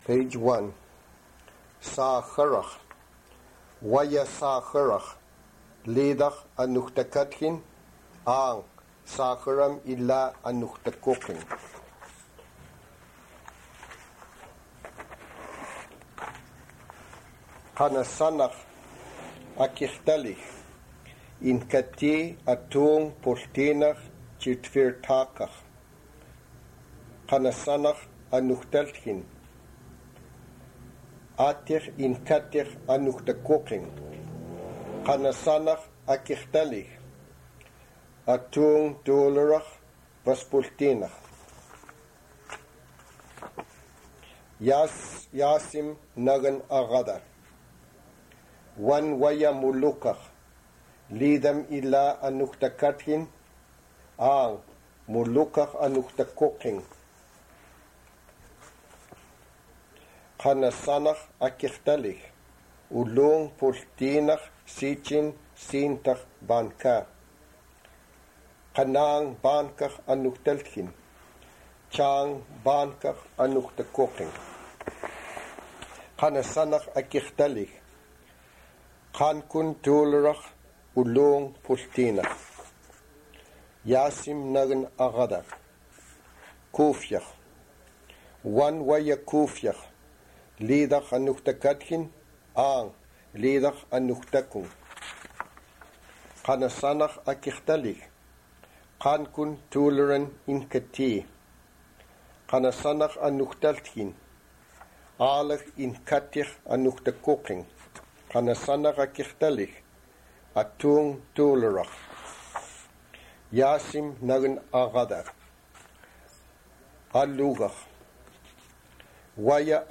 Read by